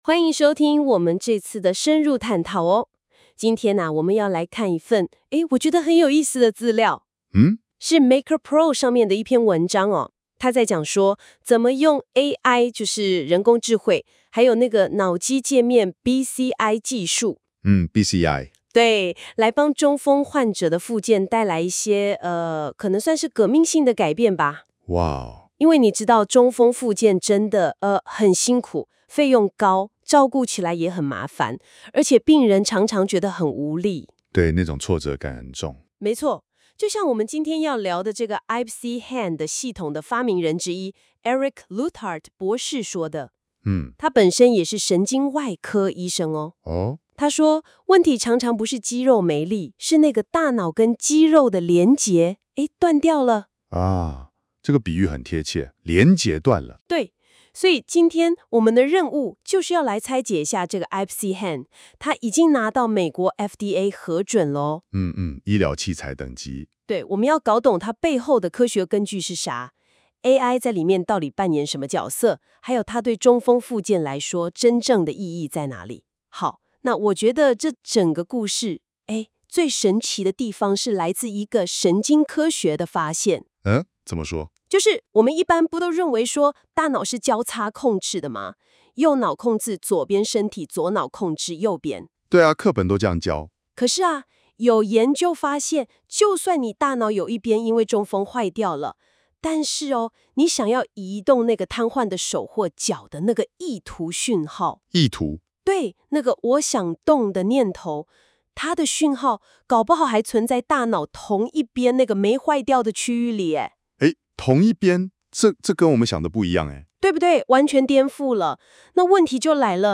喜歡用聽的？可收聽本文Podcast的精采對話喔（by GenAI）